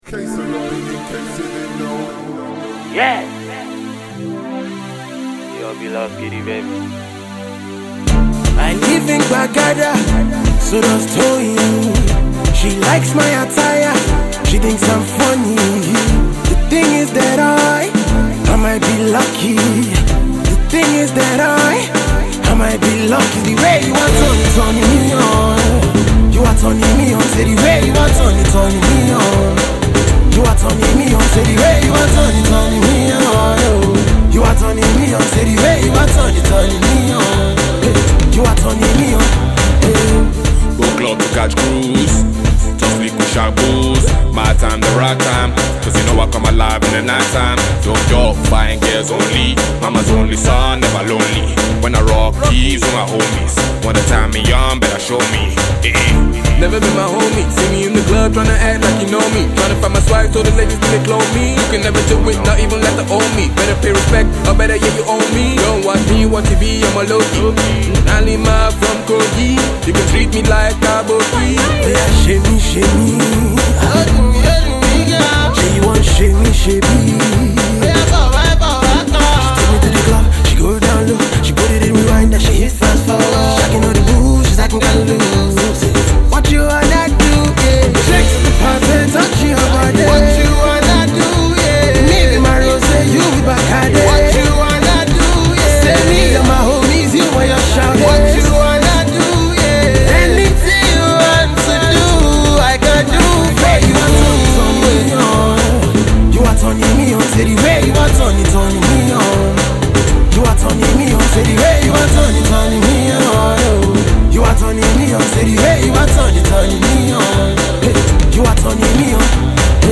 The group’s style fuses afrobeat, Hip-Hop and R&B.
playful, entertaining up-tempo song